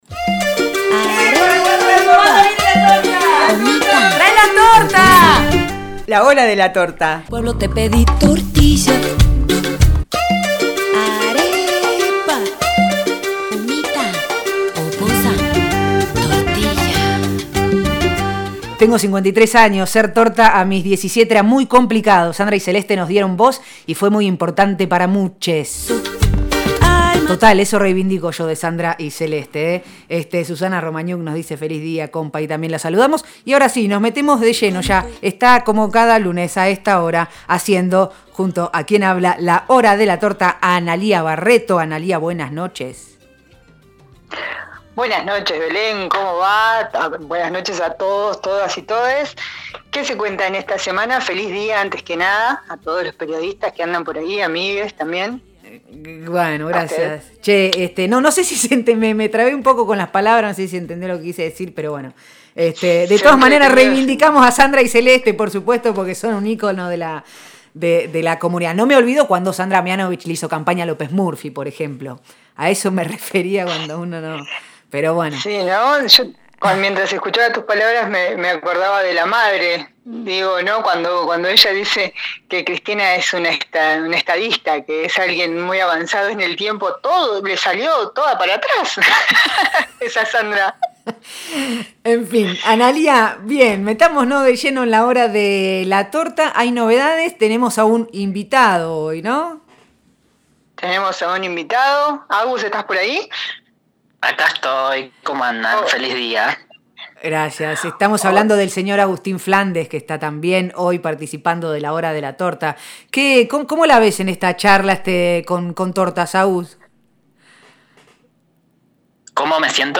una charla entre tortas que comparten información y miradas.